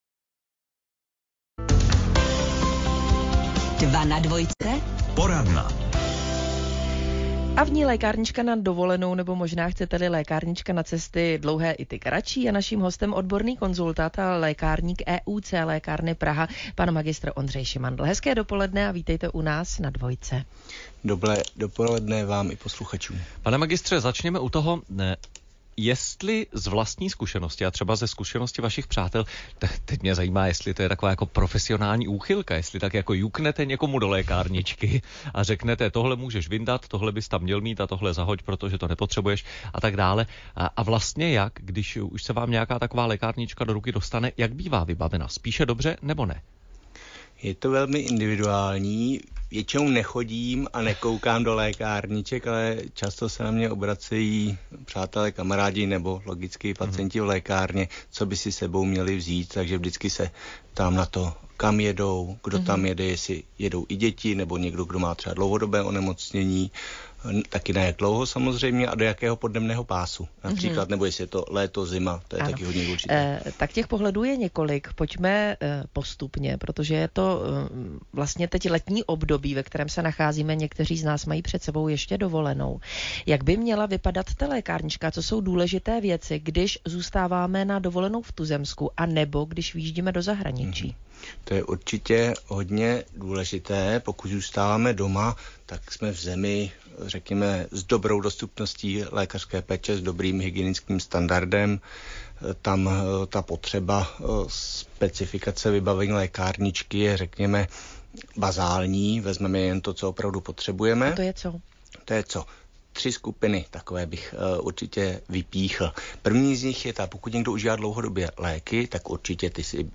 Dva Na Dvojce Rozhovor Na Téma Výbava Lékárničky Na Cesty
dva-na-dvojce_rozhovor-na-téma-výbava-lékárničky-na-cesty.mp3